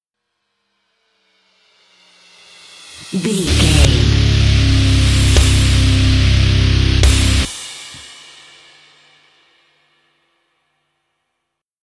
Aeolian/Minor
C#
drums
electric guitar
bass guitar
hard rock
lead guitar
aggressive
energetic
intense
nu metal
alternative metal